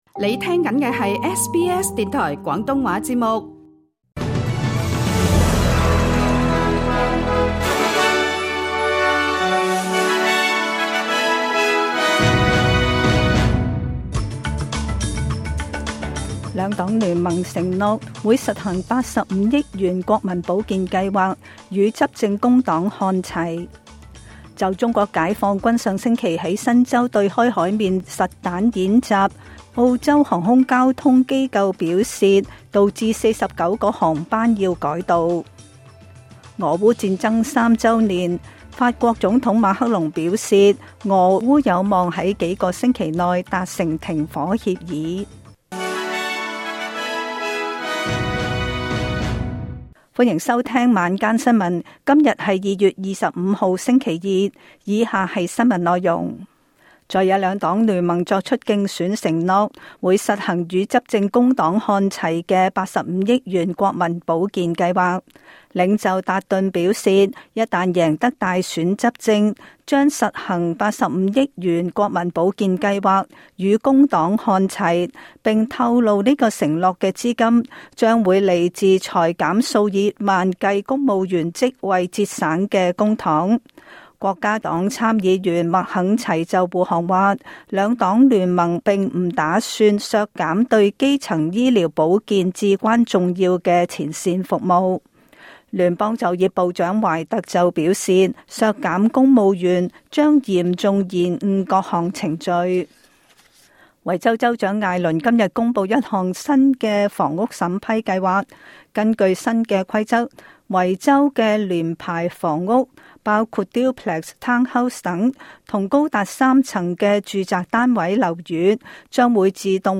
SBS廣東話晚間新聞